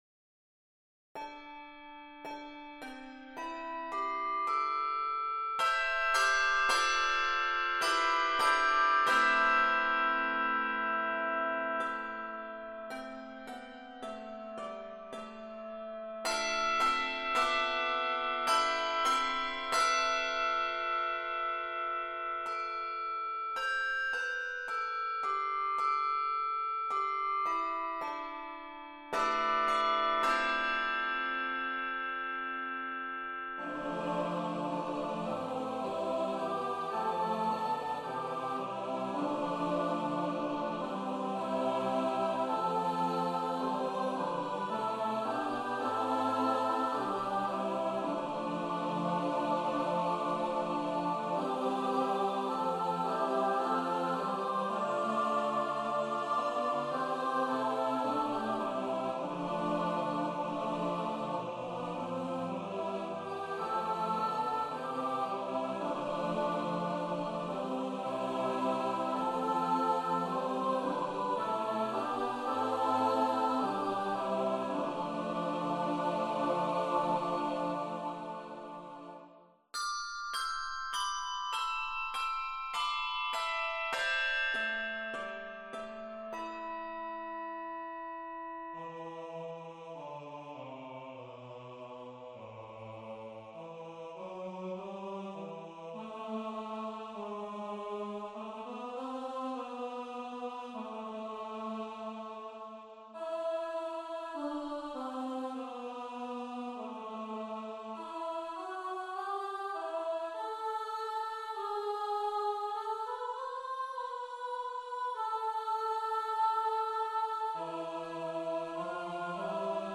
Keys of dorian mode in d, a minor, and D Major.